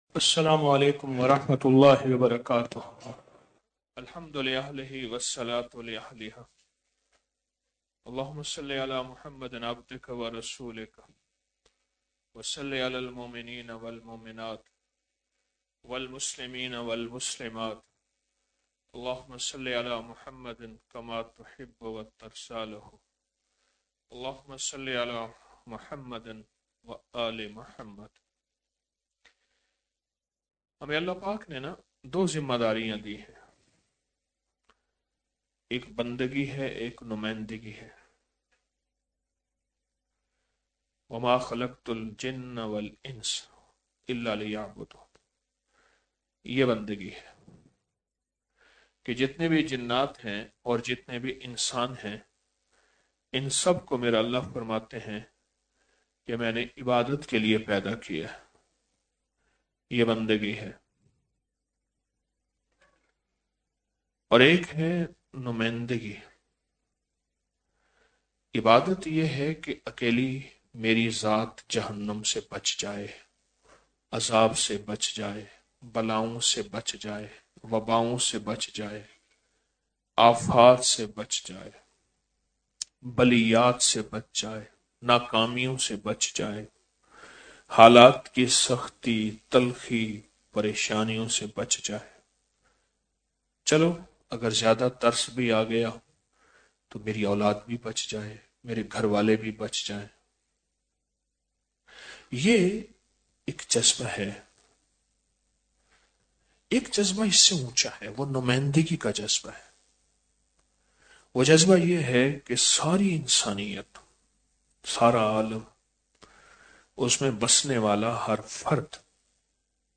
Audio Speech - Shab E Jummah Mehfil || 19 Dec 2024